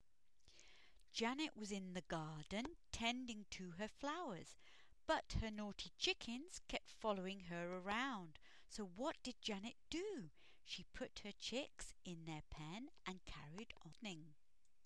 Song / rhyme